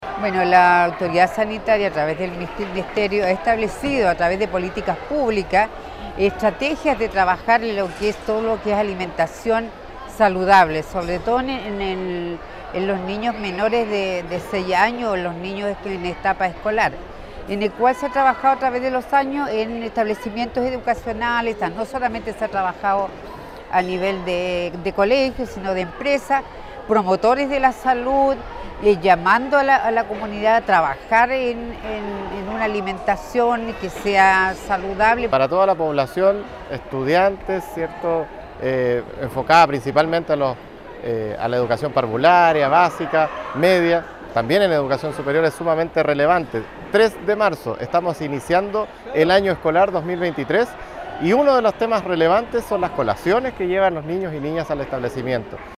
Así lo confirman la Seremi de Salud (S), Leonor Castillo, y el Seremi de Educación, Alberto Santander: